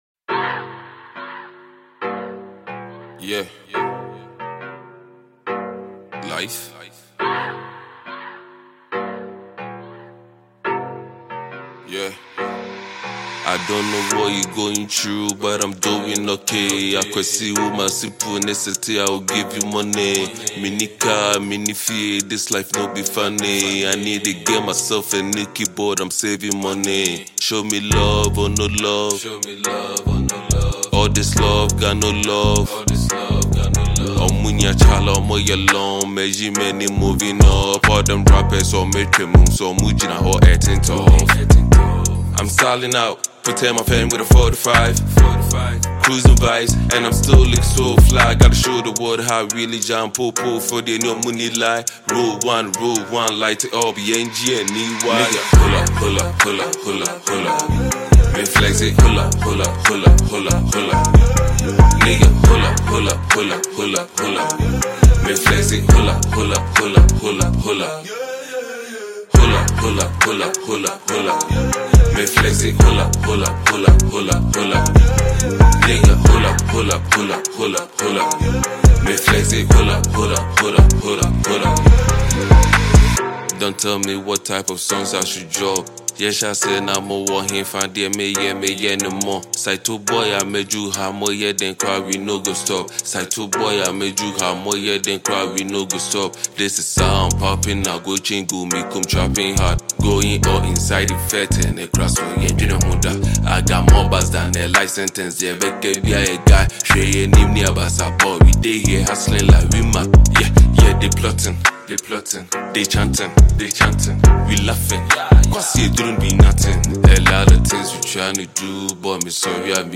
Ghana MusicMusic
Sensational Ghanaian hiphop musician